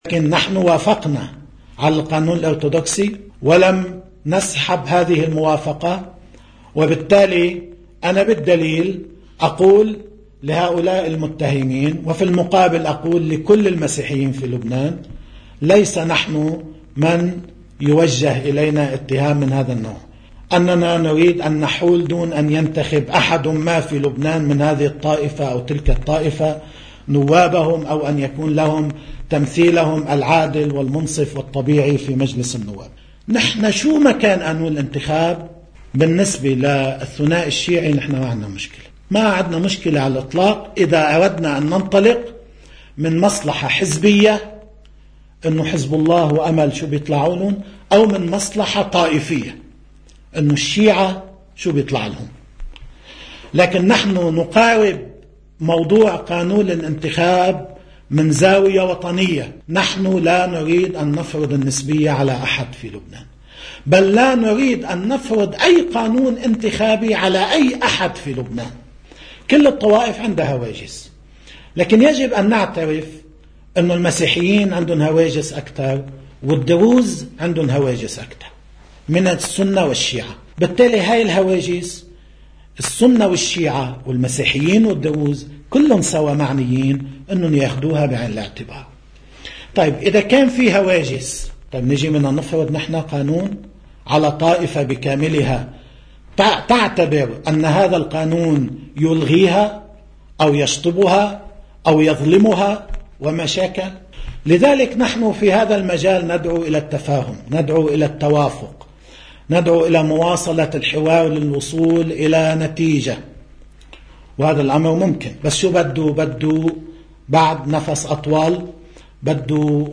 مقتطف من حديث السيّد نصرالله في يوم الجريح المقاوم: